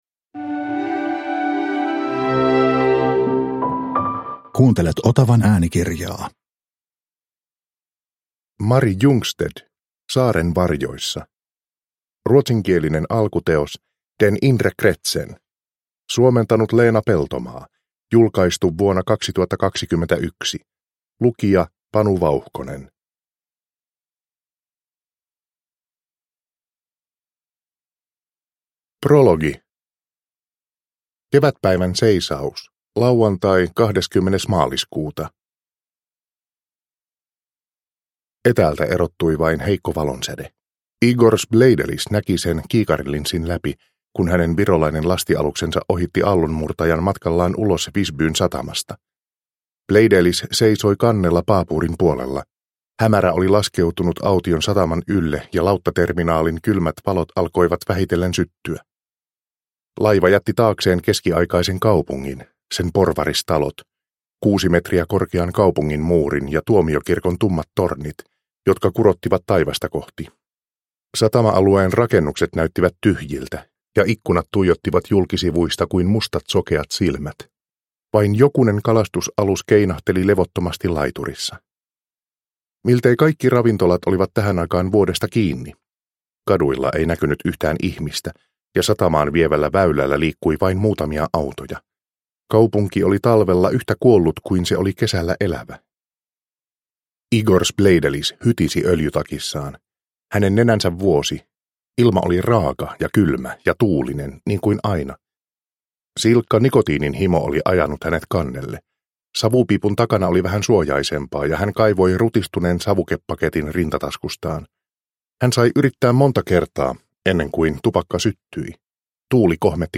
Saaren varjoissa – Ljudbok – Laddas ner